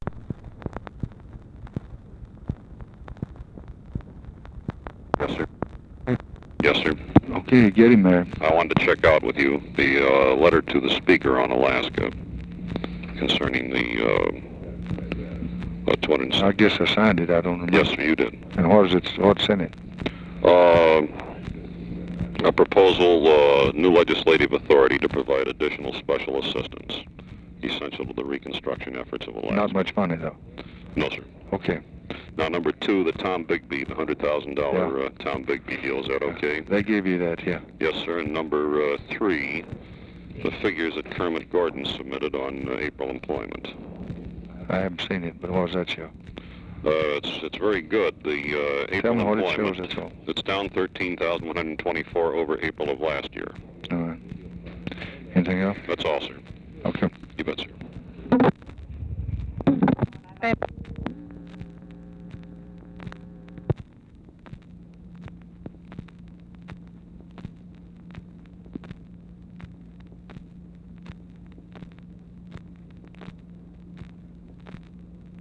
Conversation with GEORGE REEDY, May 27, 1964
Secret White House Tapes